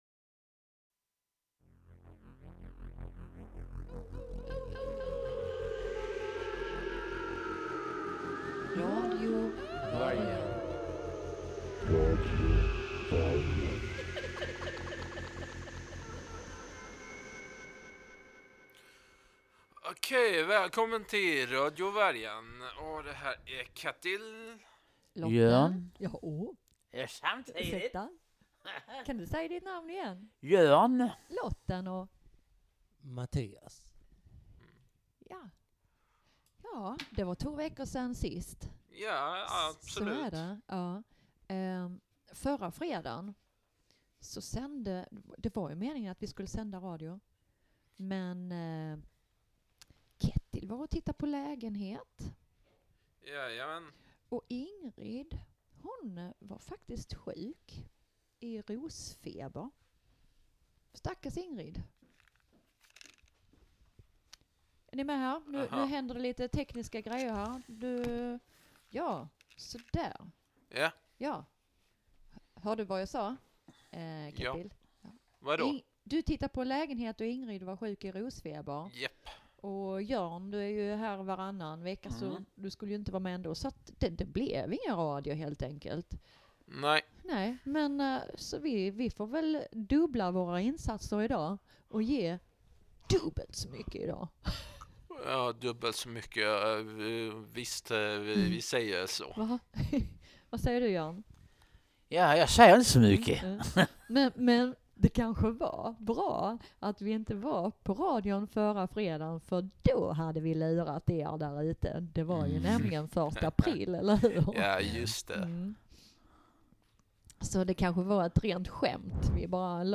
Inlevelsen är total när han återger handlingen.